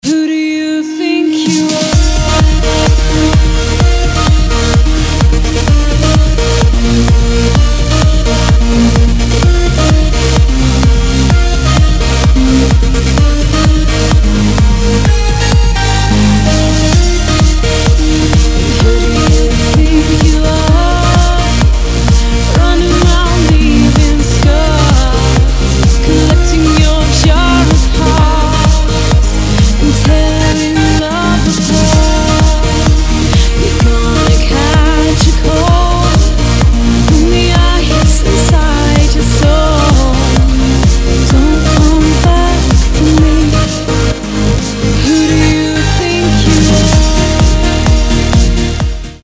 • Качество: 192, Stereo
женский вокал
Electronic
Trance
vocal trance